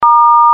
SFX经典电话按键哔飞屏蔽盲音音效下载
这是一个免费素材，欢迎下载；音效素材为经典电话按键哔飞屏蔽盲音， 格式为 mp3，大小1 MB，源文件无水印干扰，欢迎使用国外素材网。